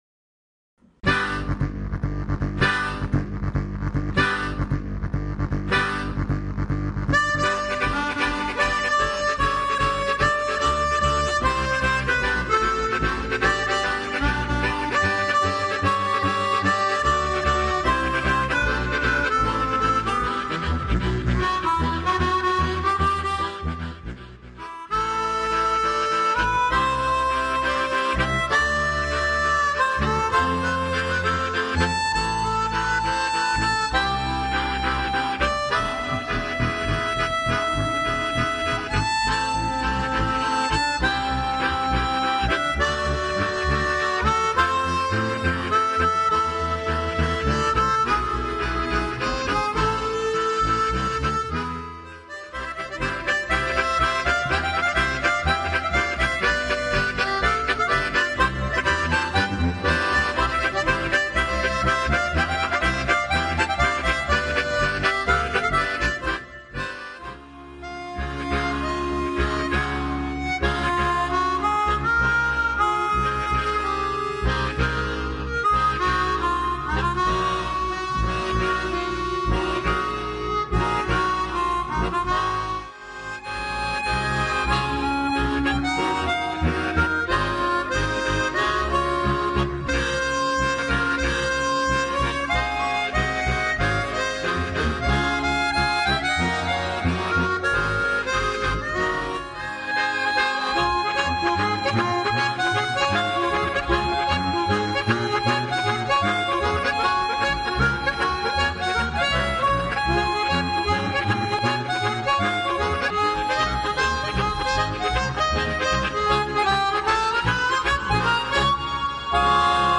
Hörprobe vom Auftritt in Klingenthal 2009 (MP3)